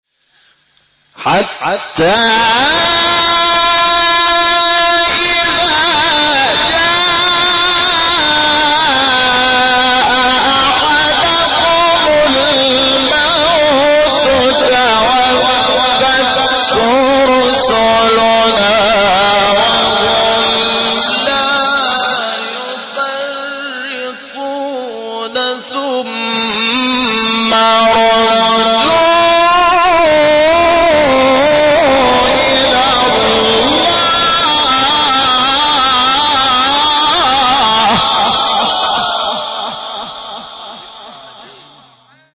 آیه 61-62 سوره انعام استاد محمود شحات | نغمات قرآن | دانلود تلاوت قرآن